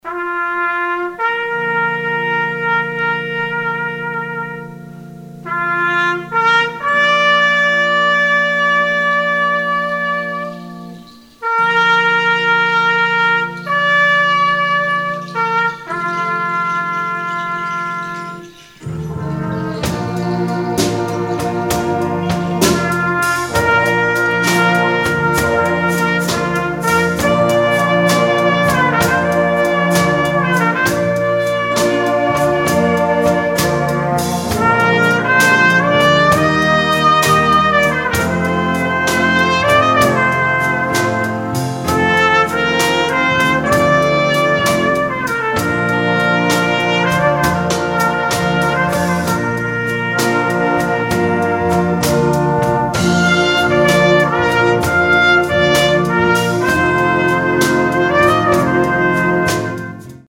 Konzert 2008 -Download-Bereich
-------Das Orchester-------